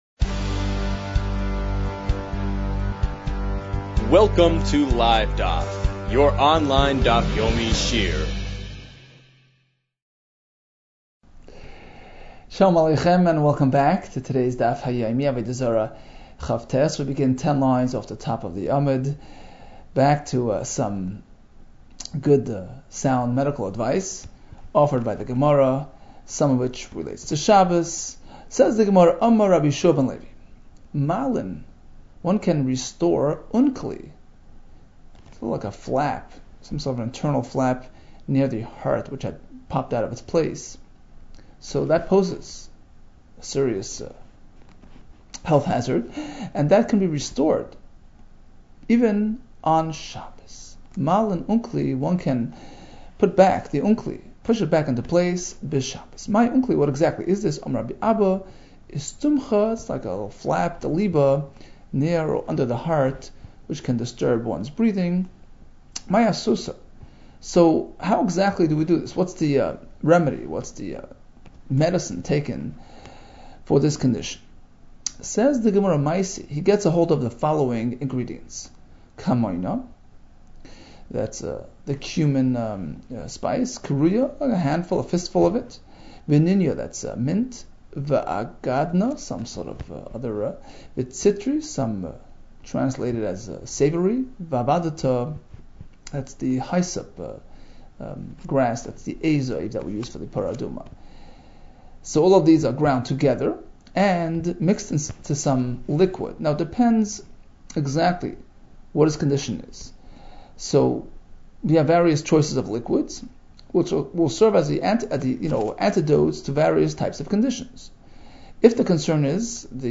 Avodah Zarah 29 - עבודה זרה כט | Daf Yomi Online Shiur | Livedaf